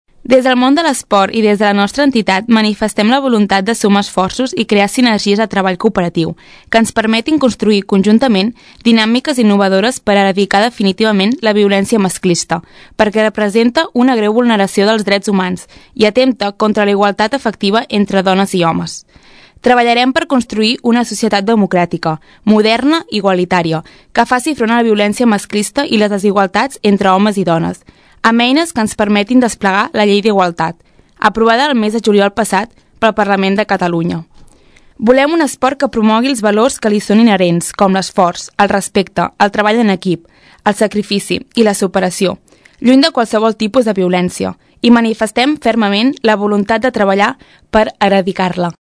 Bàrbara Vergés, regidora d’Esports de l’Ajuntament de Tordera, ens llegeix el manifest institucional.